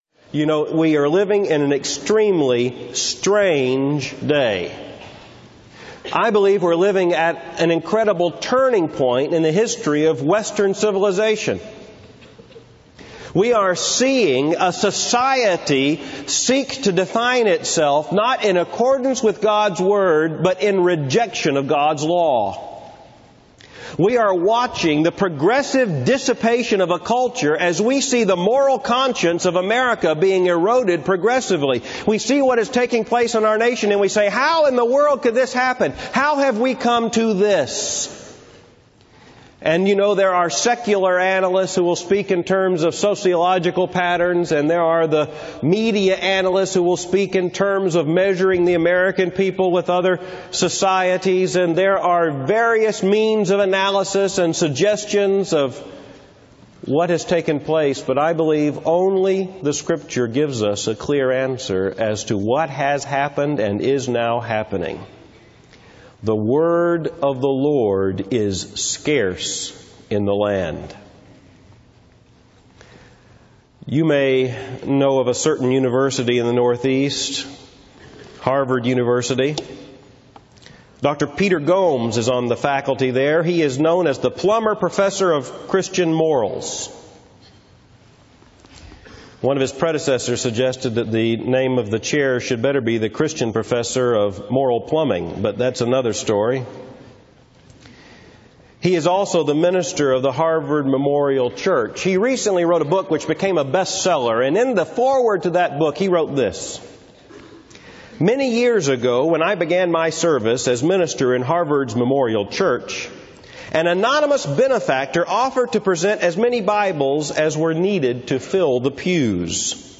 Dr. Albert Mohler is President of The Southern Baptist Theological Seminary in Louisville, Kentucky. The text for his message is Hebrews 4:12-13.